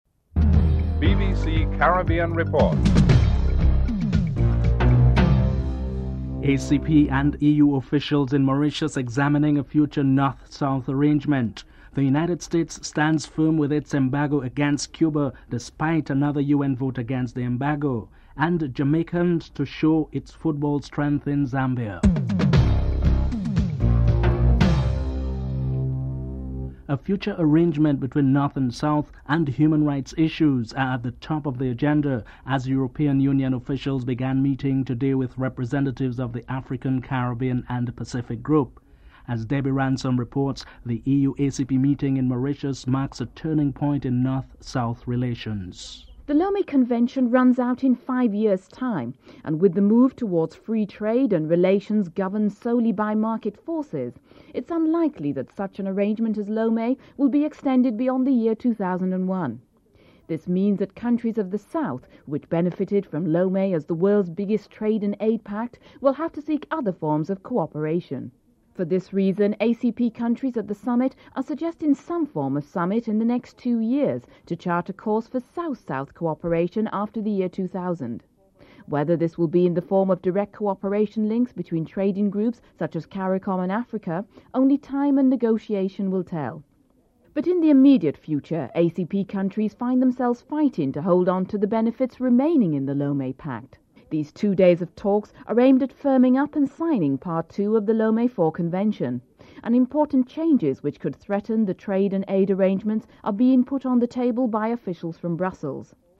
Caribbean Report took to the streets of Georgetown to find out how much attention is being paid to the inquiry.